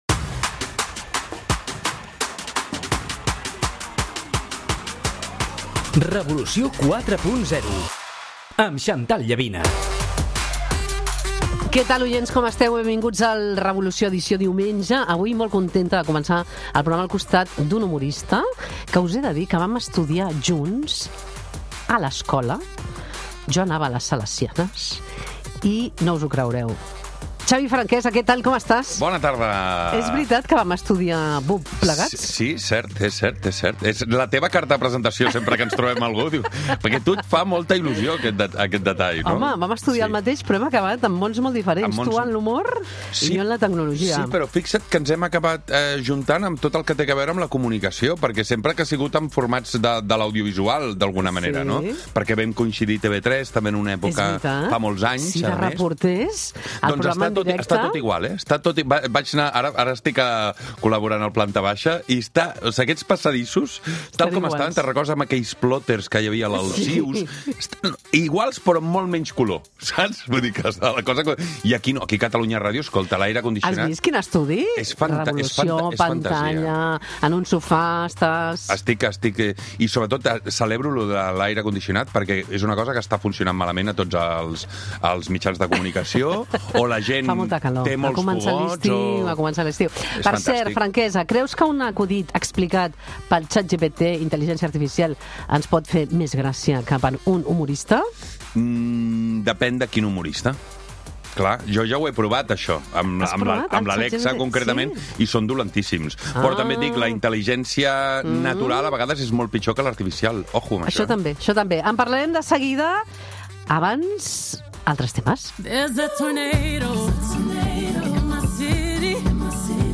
fa una entrevista